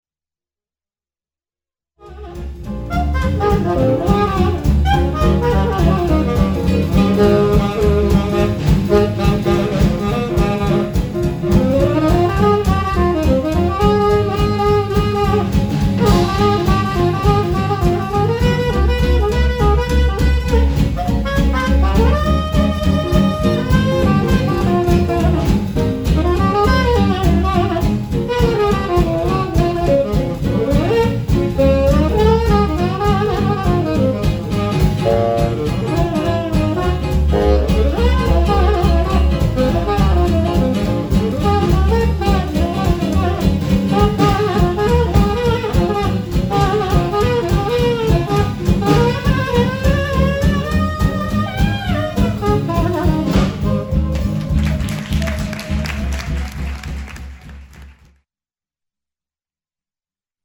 saxophone ténor
guitare
contrebasse
batterie